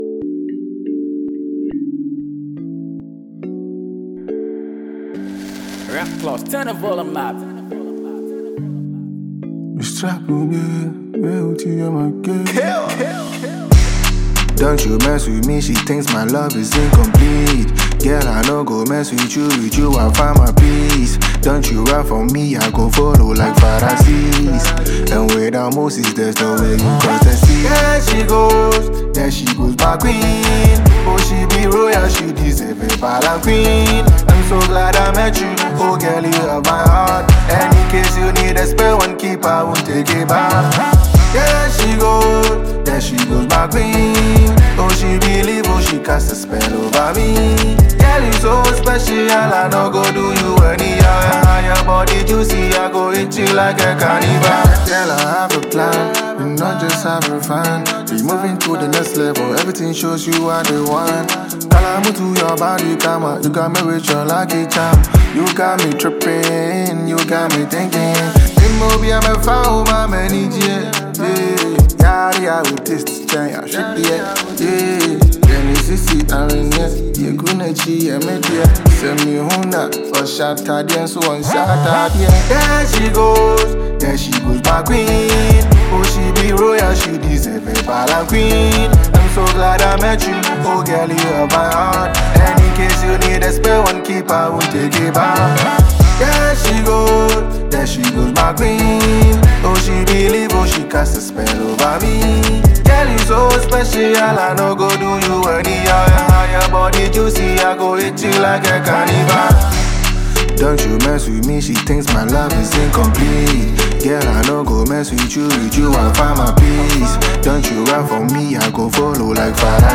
a Ghanaian trapper
Enjoy this magical production from this rapper.